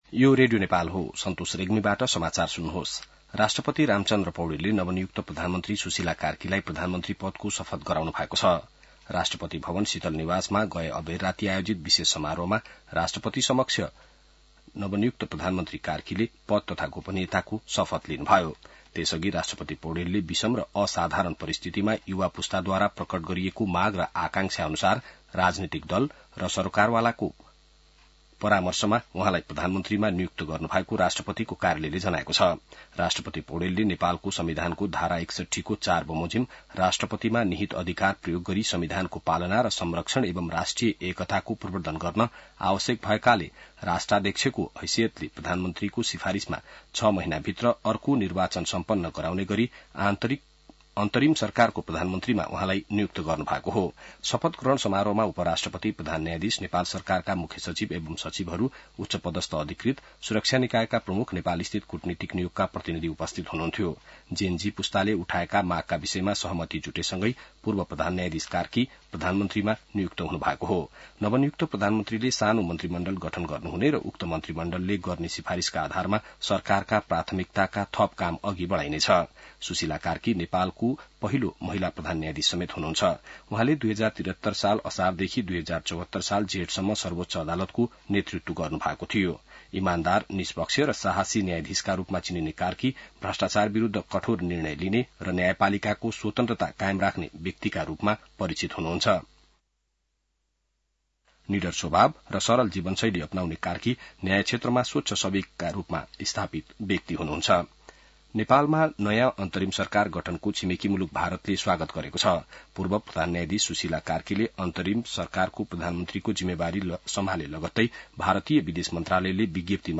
An online outlet of Nepal's national radio broadcaster
बिहान ६ बजेको नेपाली समाचार : २८ भदौ , २०८२